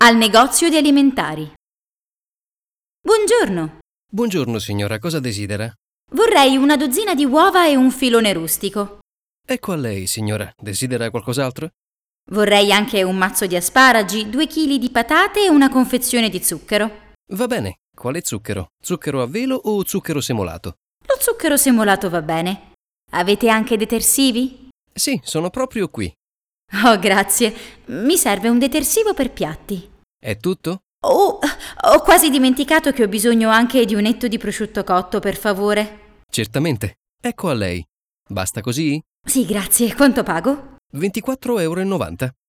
HASZNOS OLASZ PÁRBESZÉD: Al negozio di alimentari – Az élelmiszerboltban